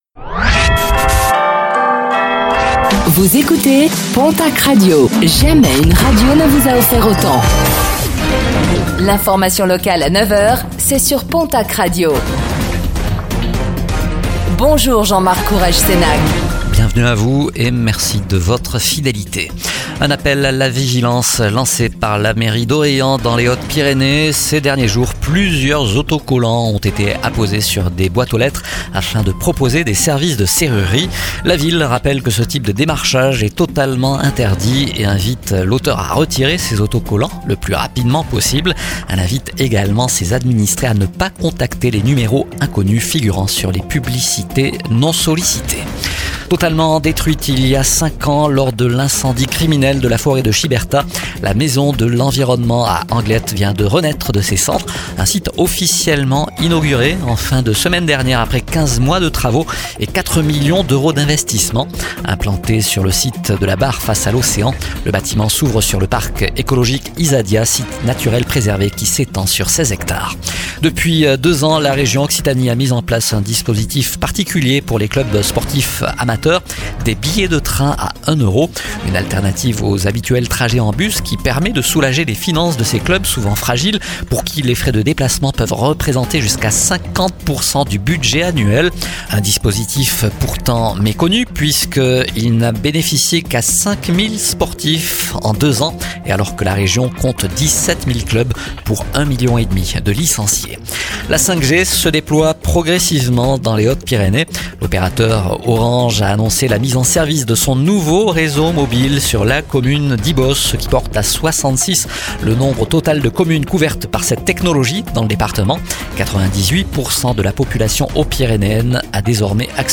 Réécoutez le flash d'information locale de ce mercredi 19 novembre 2025, présenté par